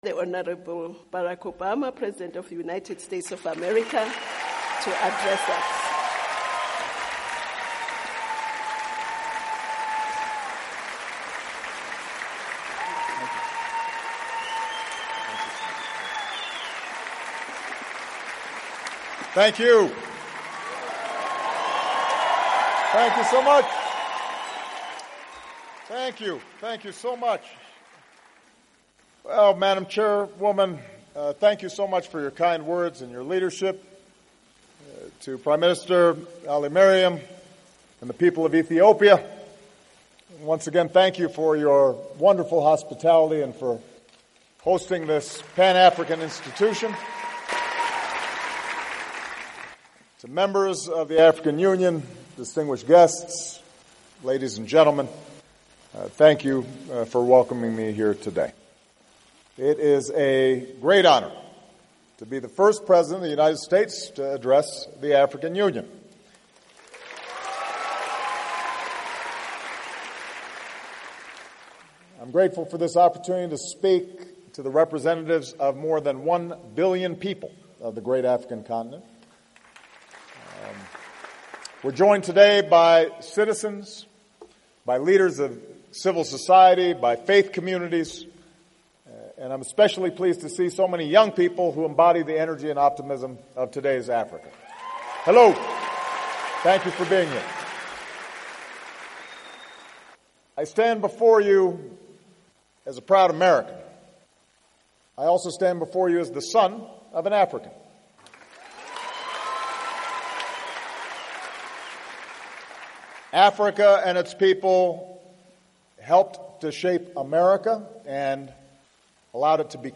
Before returning to the United States after visiting the east African countries of Kenya and Ethiopia, U.S. President Barack Obama addressed the People Of Africa from the African Union Headquarters in Addis Ababa, Ethiopia. Full Speech